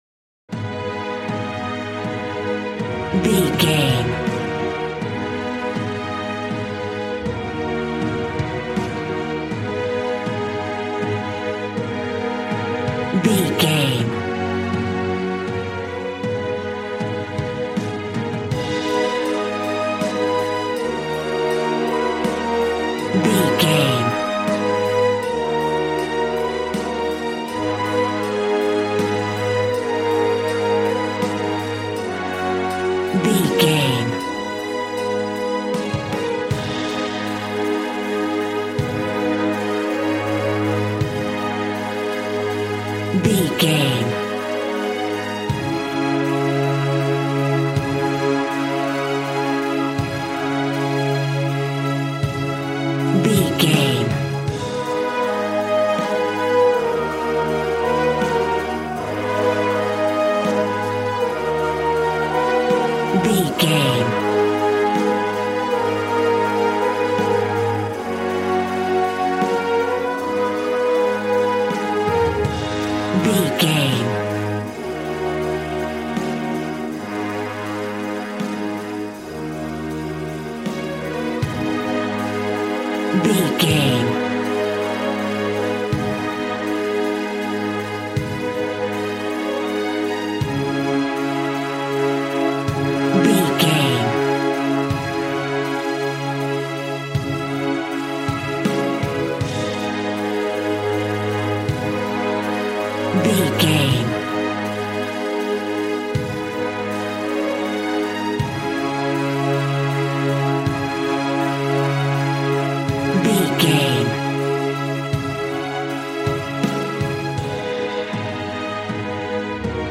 Aeolian/Minor
A♭
dramatic
epic
strings
violin
brass